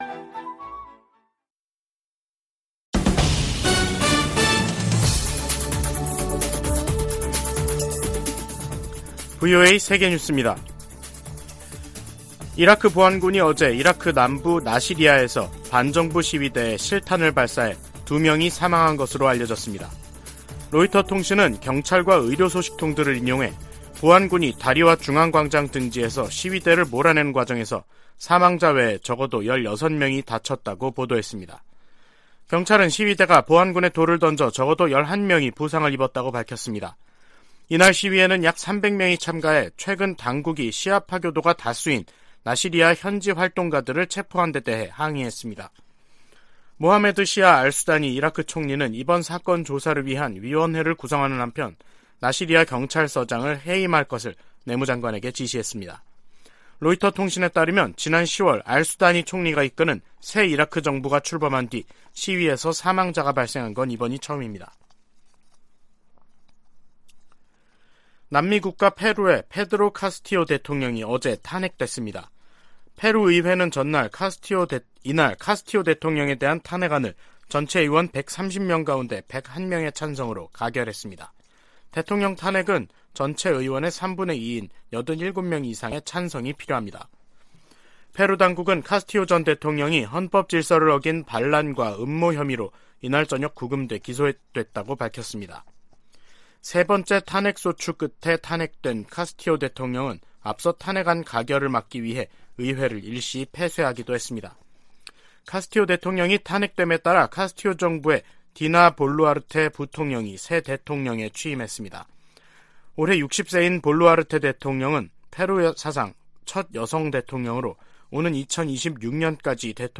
VOA 한국어 간판 뉴스 프로그램 '뉴스 투데이', 2022년 12월 8일 2부 방송입니다. 미국은 북한 정권의 계속된 도발에 대응해 한국, 일본 등과 연합 훈련과 협력을 계속할 것이라고 백악관 고위관리가 밝혔습니다. 오는 12～13일 인도네시아 자카르타에서 미한, 한일, 미한일 북 핵 수석대표 협의가 열립니다.